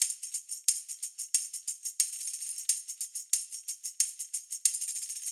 Index of /musicradar/sampled-funk-soul-samples/90bpm/Beats
SSF_TambProc1_90-02.wav